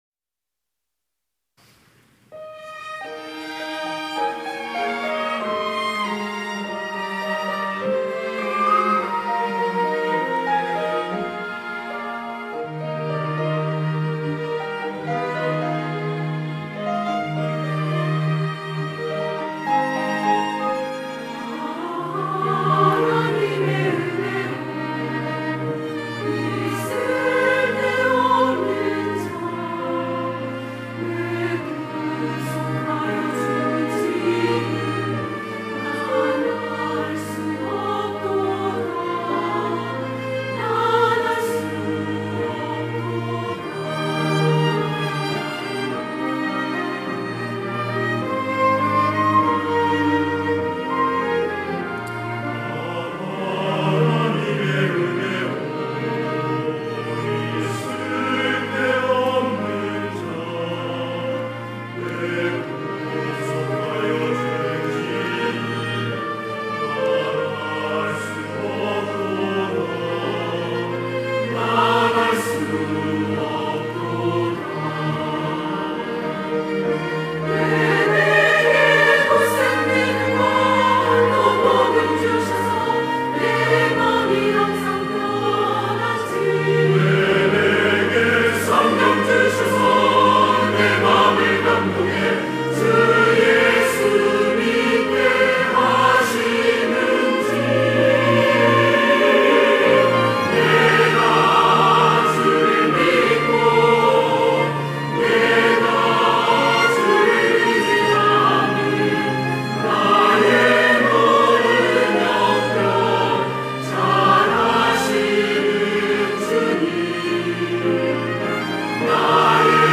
할렐루야(주일2부) - 아 하나님의 은혜로
찬양대